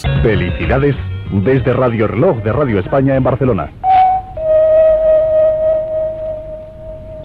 Felicitació de Nadal i toc de l'hora